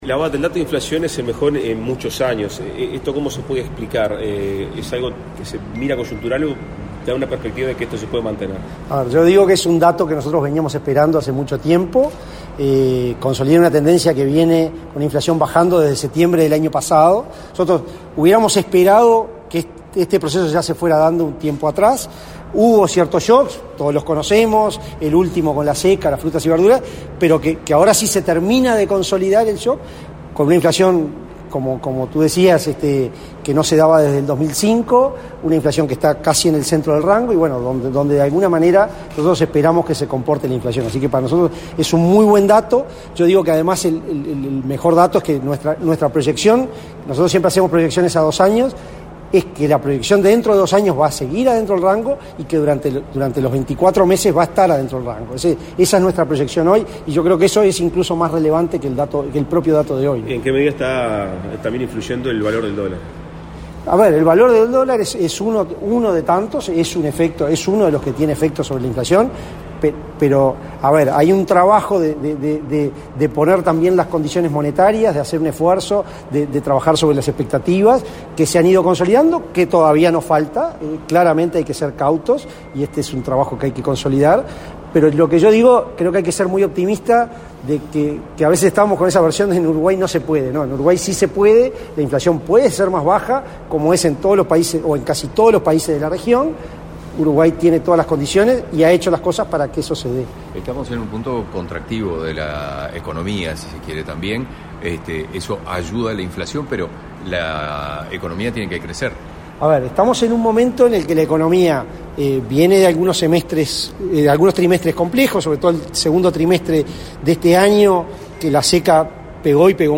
Declaraciones a la prensa del presidente del BCU, Diego Labat
Declaraciones a la prensa del presidente del BCU, Diego Labat 03/08/2023 Compartir Facebook X Copiar enlace WhatsApp LinkedIn Tras reunirse con el secretario de la Presidencia, Álvaro Delgado, este 3 de agosto, el presidente del Banco Central del Uruguay (BCU), Diego Labat, realizó declaraciones a la prensa.